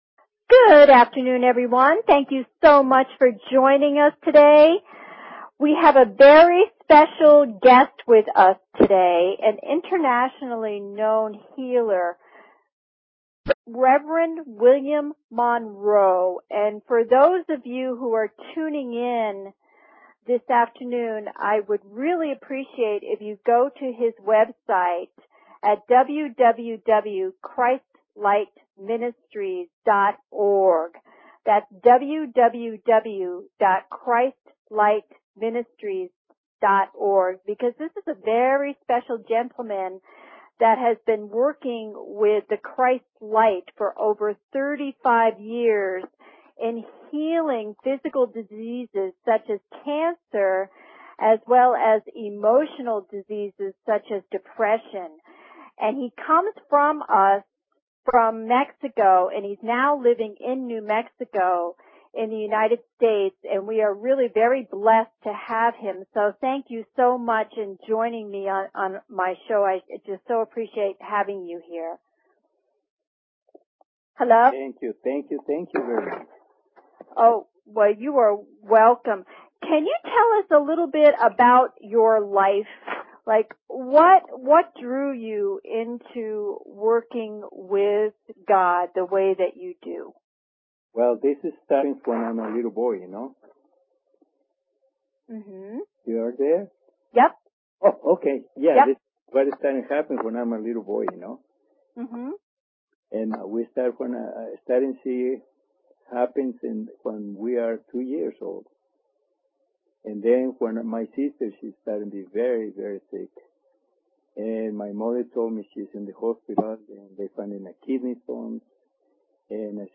Talk Show Episode, Audio Podcast, Psychic_Connection and Courtesy of BBS Radio on , show guests , about , categorized as
Interview